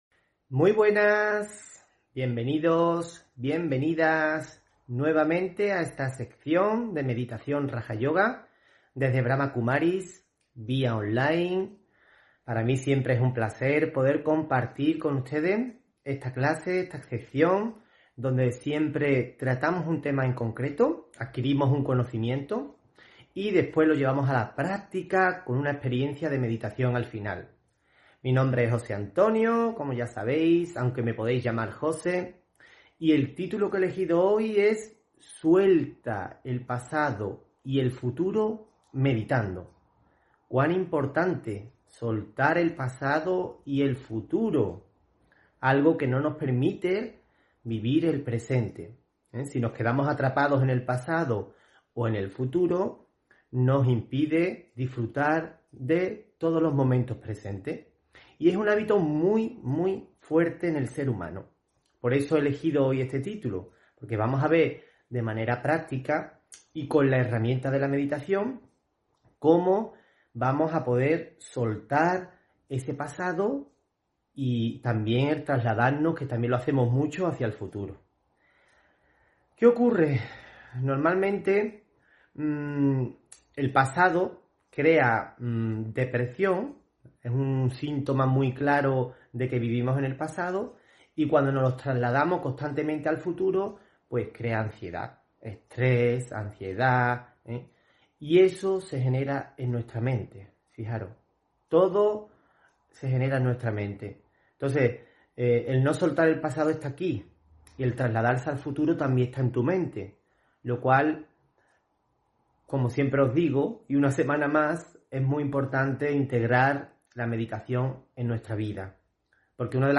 Audio conferencias
Meditación y conferencia: Suelta el pasado y el futuro meditando (12 Enero 2022)